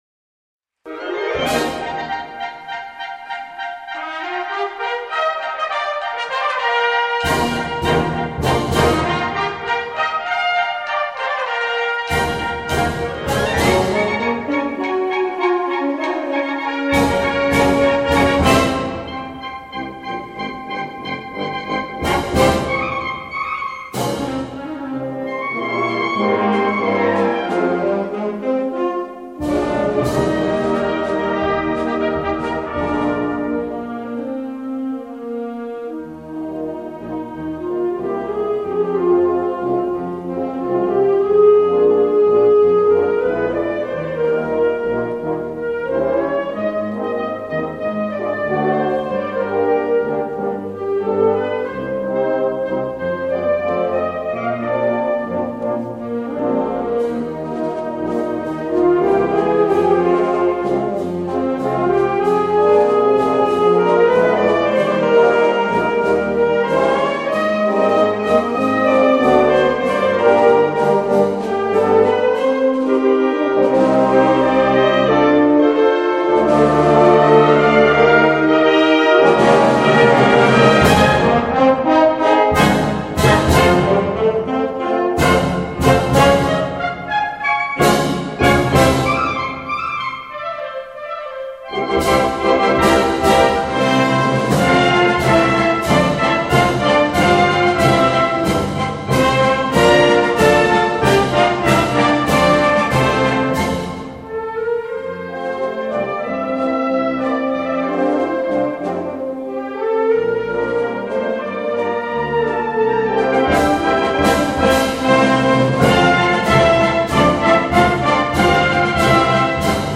Pasdoble